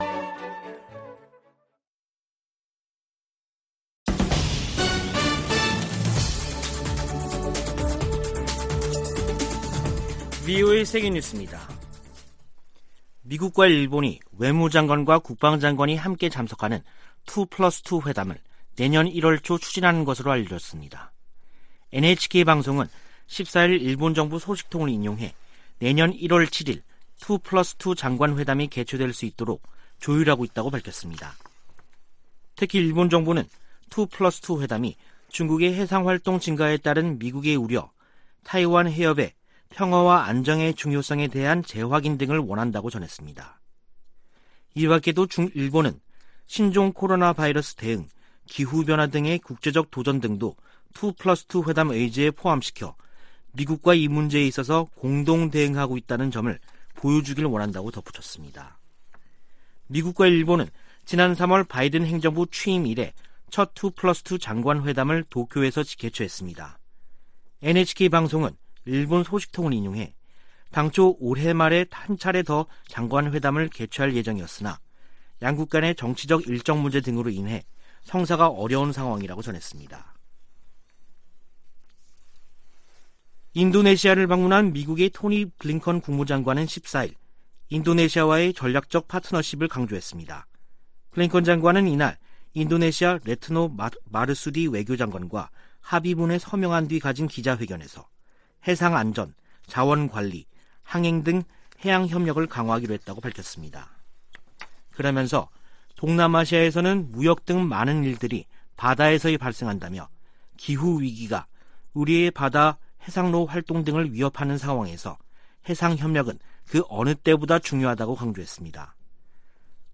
VOA 한국어 간판 뉴스 프로그램 '뉴스 투데이', 2021년 12월 15일 2부 방송입니다. 미국은 북한과 진지하고 지속적인 외교를 추구하고 있다고 토니 블링컨 국무장관이 밝혔습니다. 미 국무부는 북한의 해외 강제노역 관련 정보를 수집하고 있다면서 내용을 알고 있는 이들에게 정보를 제공해줄 것을 요청했습니다. 북한의 최근 탄도미사일 시험은 역내 미사일 방어 약화를 겨냥한 것으로 보인다고 미 의회조사국이 밝혔습니다.